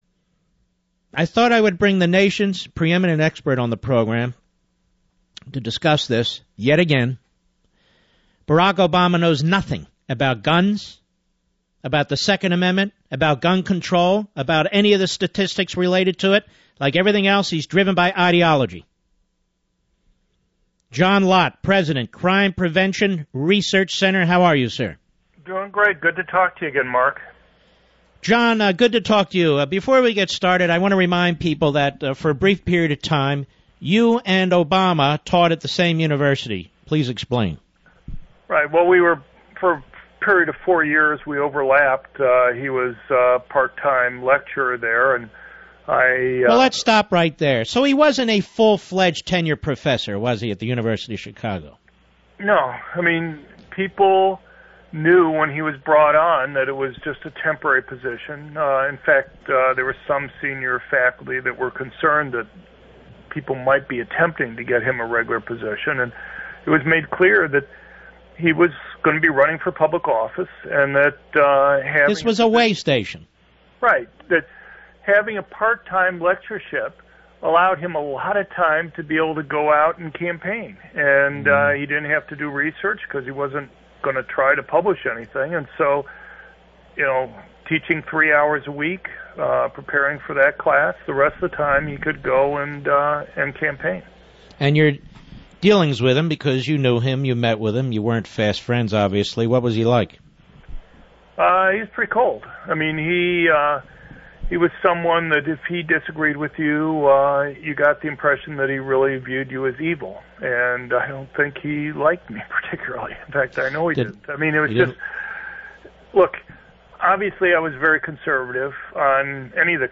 CPRC on the Mark Levin radio show to discuss the Oregon Community College Shooting